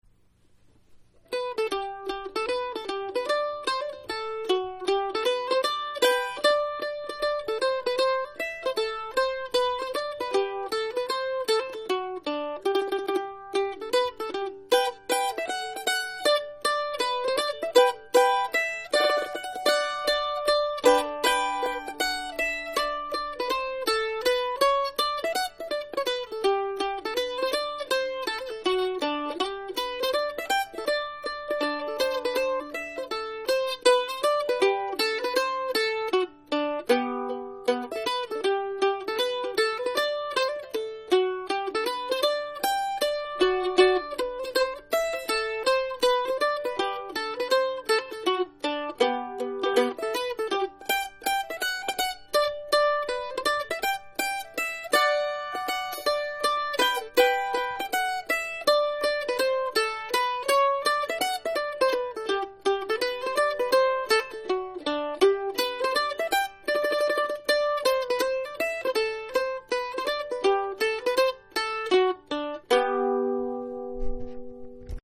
Morris & Country Dance